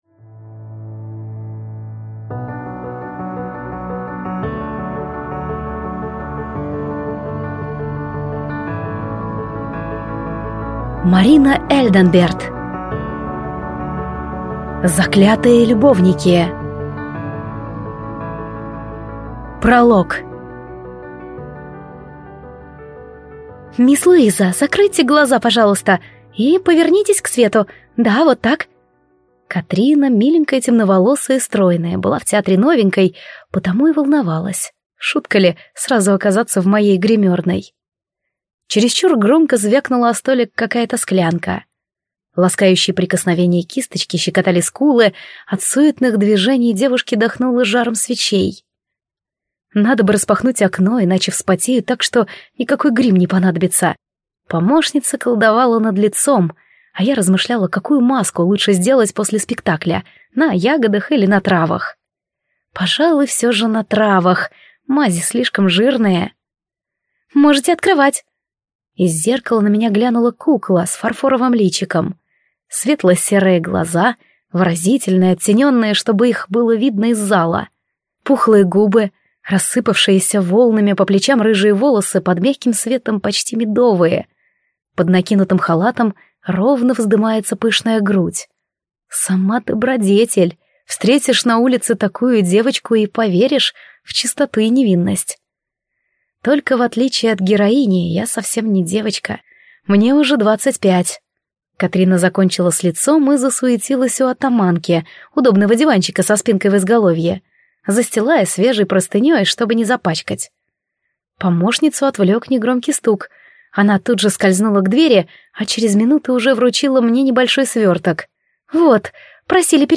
Аудиокнига Заклятые любовники | Библиотека аудиокниг